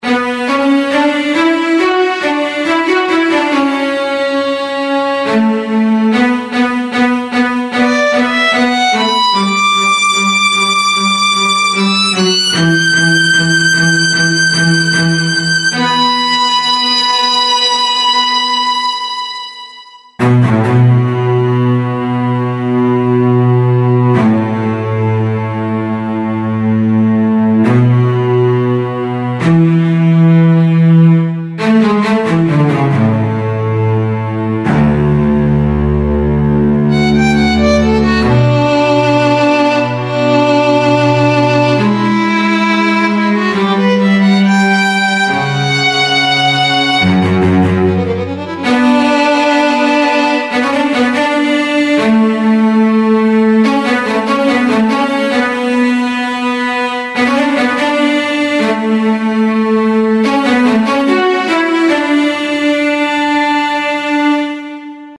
HALion6 : Studio Strings
LivelySolo